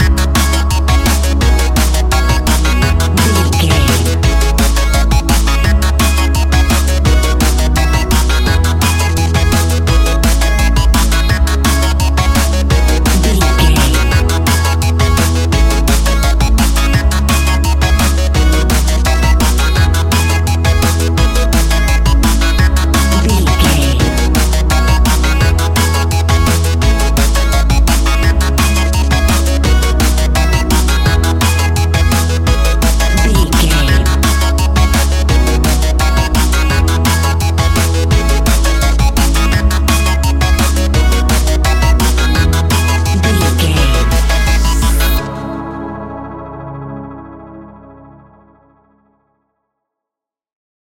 Ionian/Major
techno
trance
synths
synthwave
instrumentals